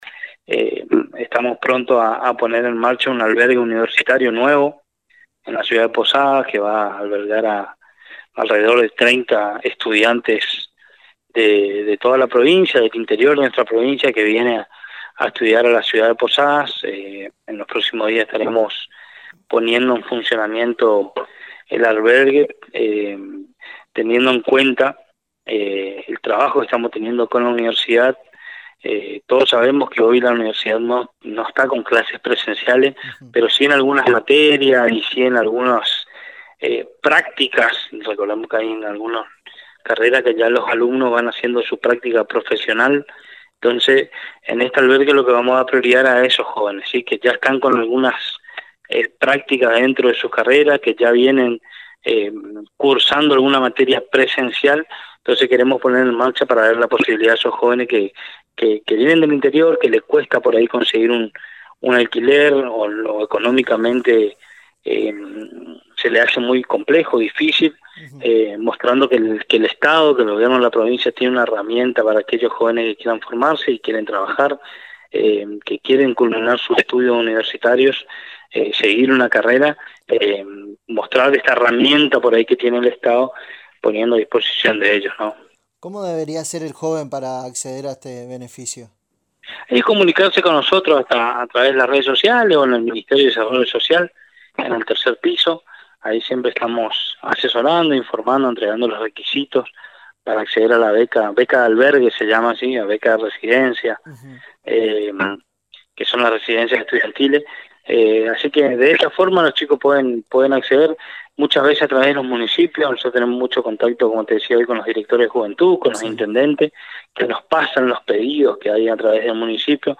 El subsecretario de Juventud de la provincia de Misiones Pablo Nuñez, en ocasión de la presentación de la segunda edición del programa “Jóvenes Al Volante” contó sobre el programa de albergues estudiantiles que estarán lanzando en los próximos días, como así también de labores en conjunto con el Ministerio de Prevención de Adicciones y Control de Drogas con una campaña en el mes de lucha contra las adicciones.